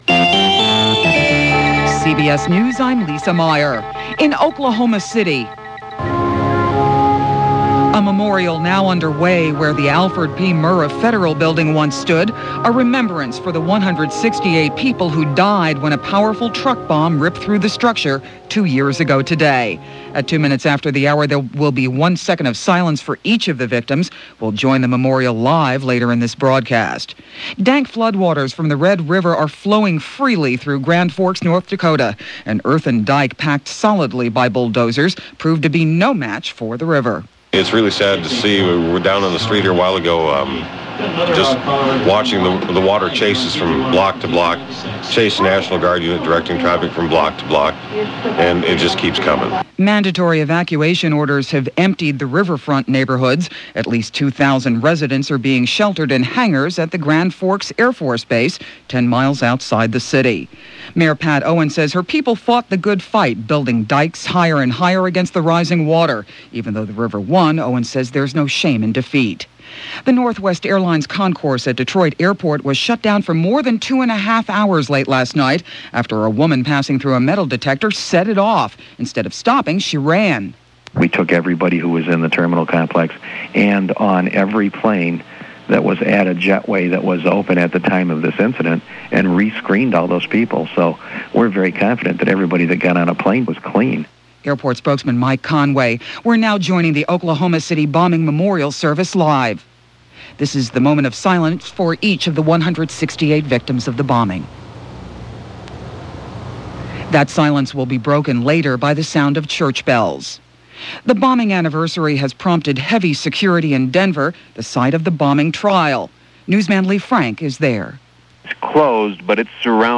And that’s a sample of what went on, this April 19, 1997 as broadcast by CBS Radio Hourly News.